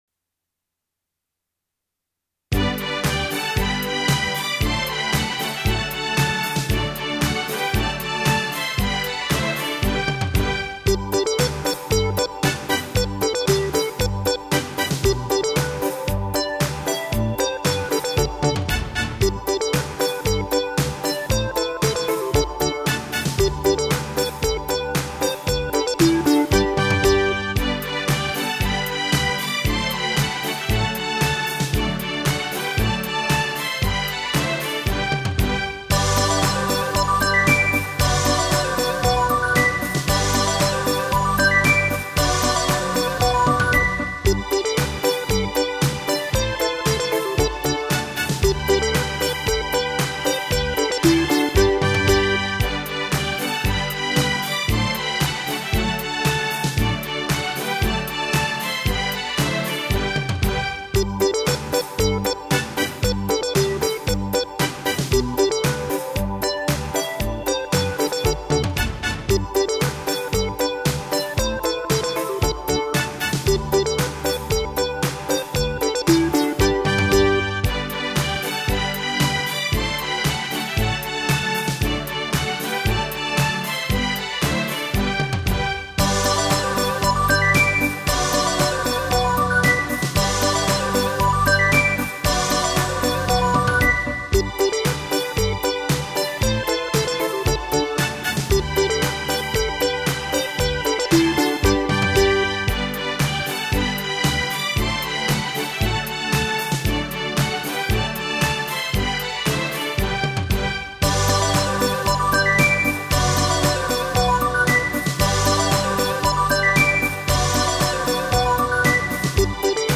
锡伯风